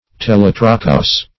Search Result for " telotrochous" : The Collaborative International Dictionary of English v.0.48: Telotrochal \Te*lot"ro*chal\, Telotrochous \Te*lot"ro*chous\, a. [Gr.